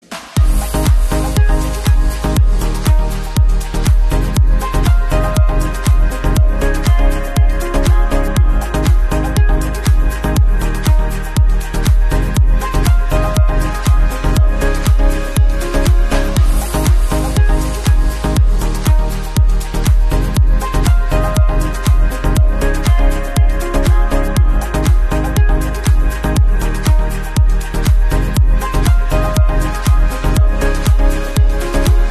[通知鈴聲][三星][Galaxy Watch 7]三星Galaxy Watch 7所有通知鈴聲